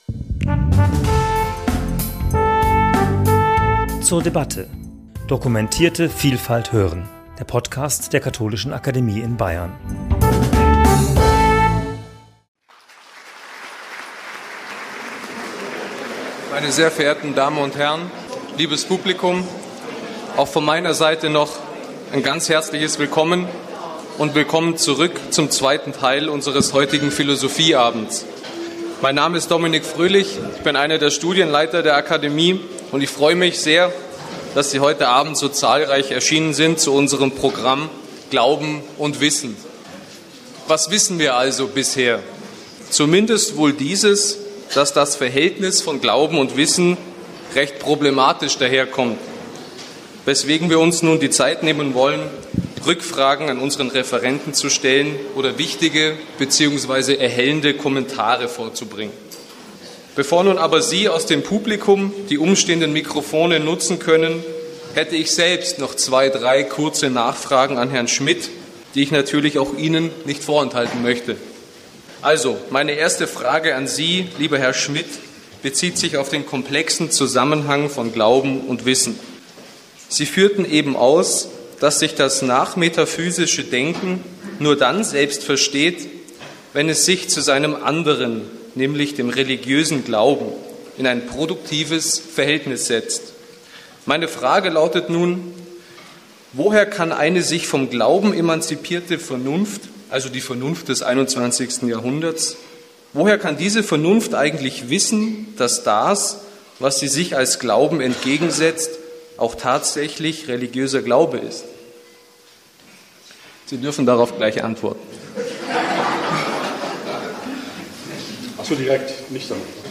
Am 14. Januar 2020 haben wir uns in der Katholischen Akademie in Bayern darum bemüht, das Denken von Jürgen Habermas zu ergründen. Im Mittelpunkt stand die Frage, wie sich Glauben und Wissen zueinander verhalten.